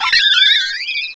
sovereignx/sound/direct_sound_samples/cries/staravia.aif at master